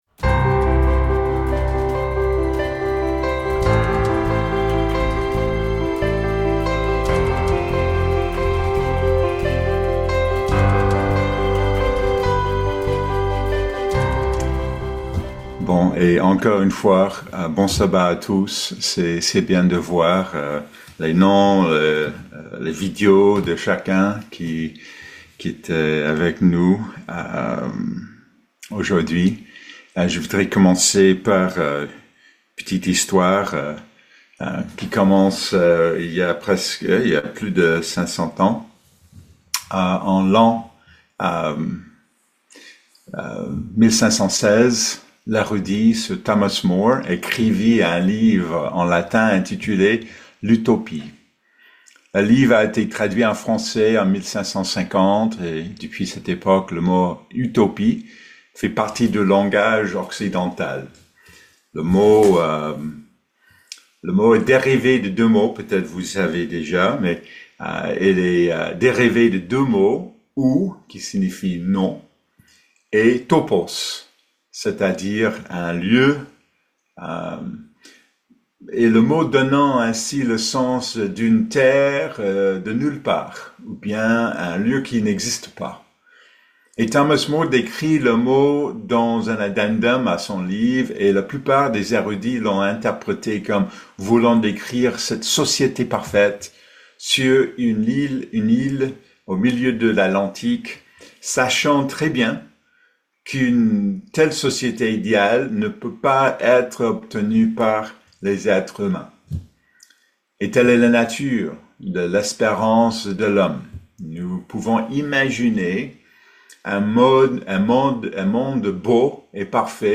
Notre quête de l’utopie | Eglise de Dieu Unie